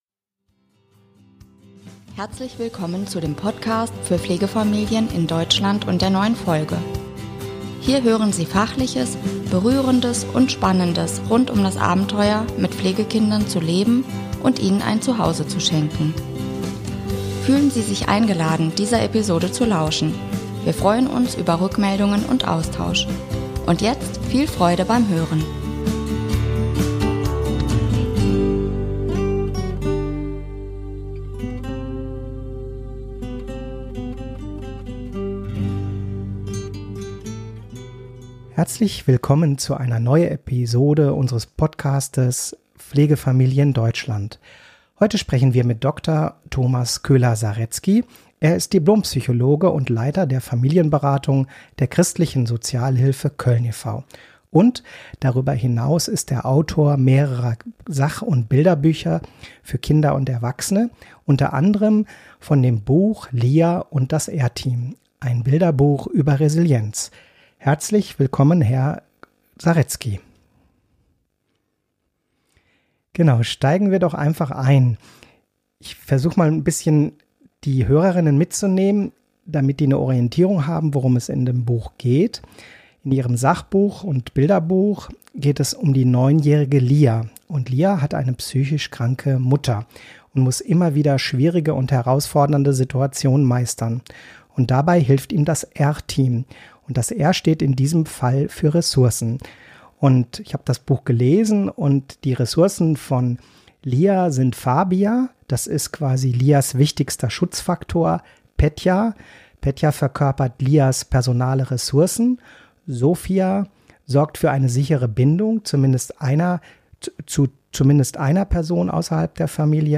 Fragen für das Interview: